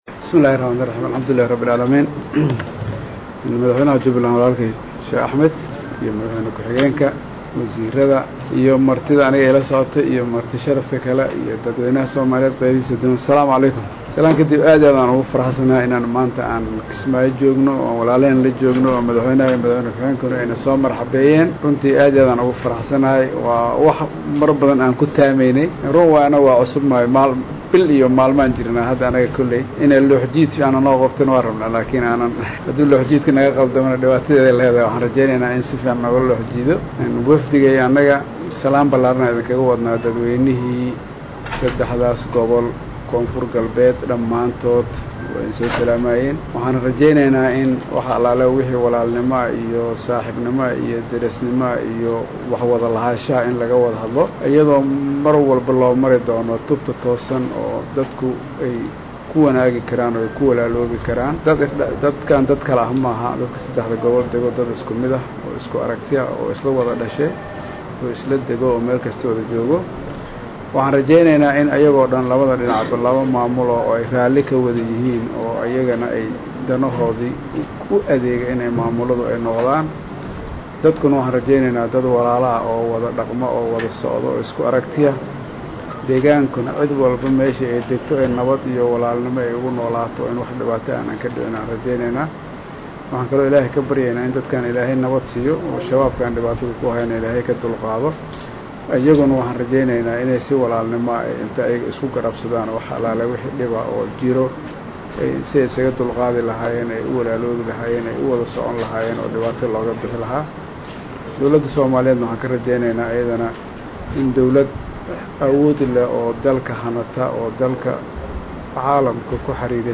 Halkan Ka Dhageyso Codka Madaxweynaha Dowlada Koonfur Galbeed Soomaaliya Shariif Xasan.
DHAGEYSO_-Hadalkii-madaxweynaha-Koonfur-Galbeed-uu-ka-jeediyay-magaalada-Kismaay.mp3